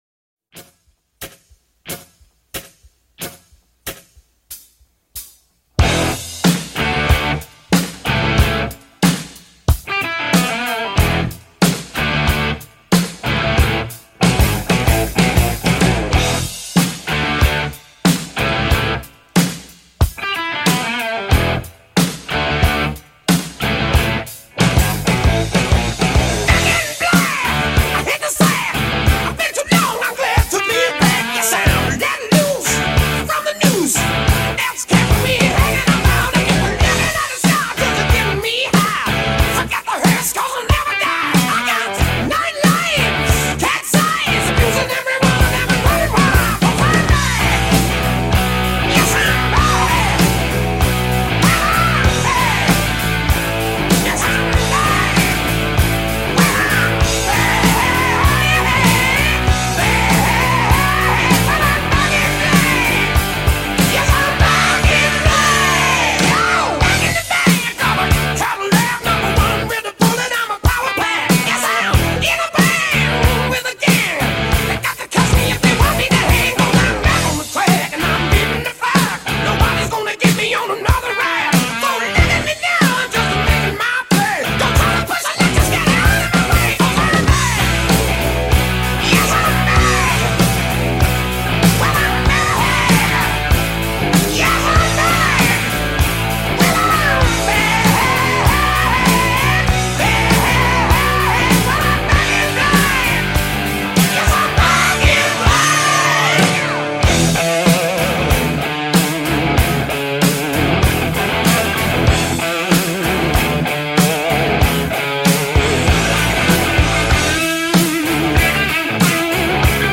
I know I said it was coming next Wednesday but the episode was ready so I decided to post early. had some difficulty with the text to speech hence why it's a bit strange but it seems to be ok enough to post